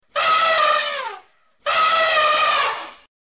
Elephant d'Asie
AUDIO ELEPHANT BARISSEMENTS
Elephan-barissements.mp3